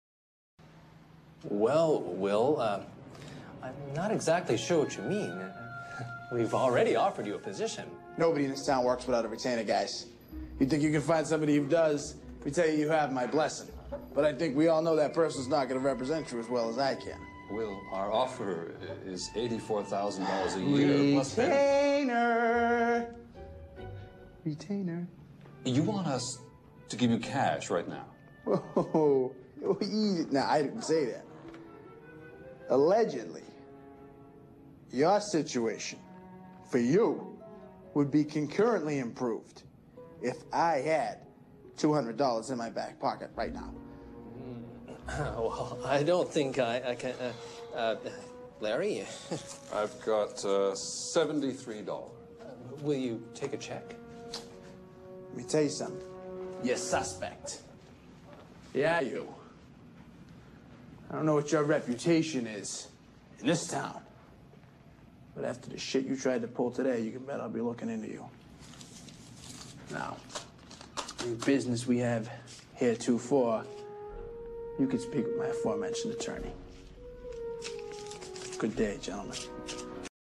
在线英语听力室影视剧中的职场美语 第7期:谈论福利待遇的听力文件下载,《影视中的职场美语》收录了工作沟通，办公室生活，商务贸易等方面的情景对话。每期除了精彩的影视剧对白，还附有主题句型。